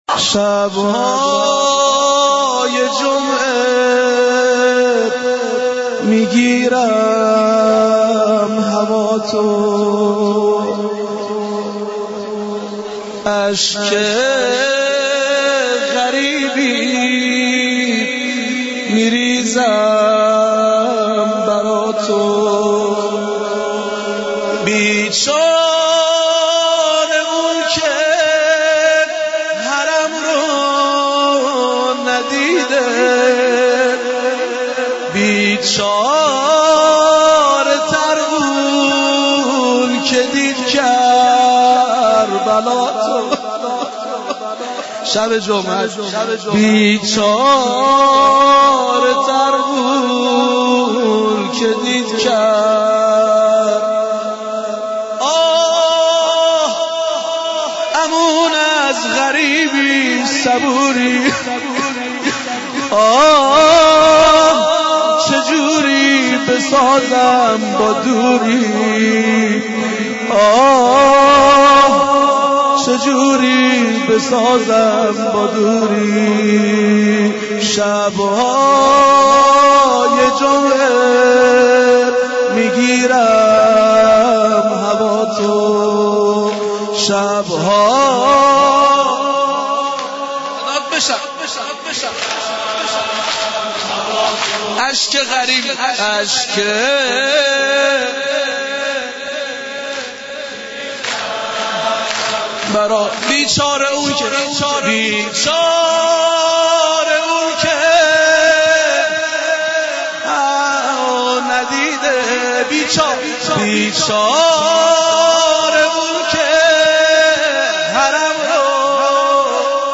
مراسم مناجات با خدا در حسینیه ام البنین(س) اهواز